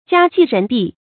家驥人璧 注音： ㄐㄧㄚ ㄐㄧˋ ㄖㄣˊ ㄅㄧˋ 讀音讀法： 意思解釋： 喻指優秀人才。